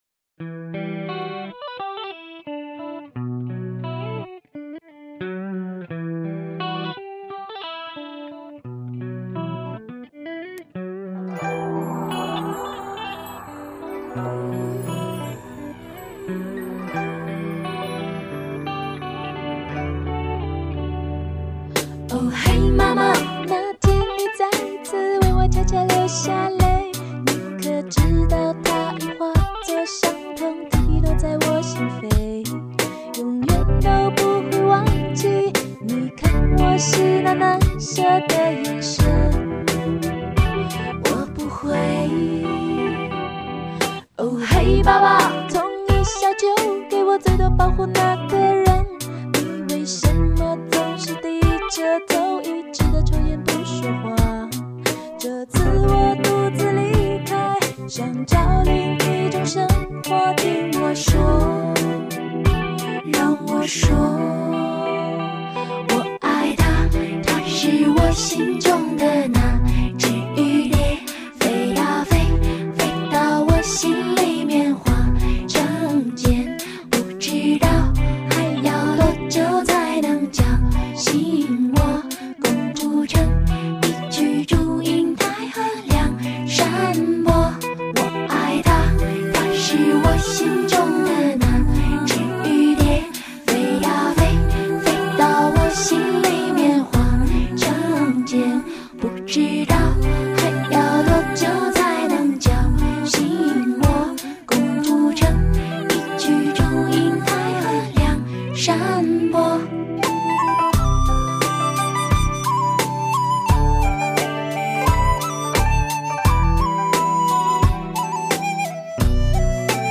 忍不住就翻啦　 没加任何音效。是原音哦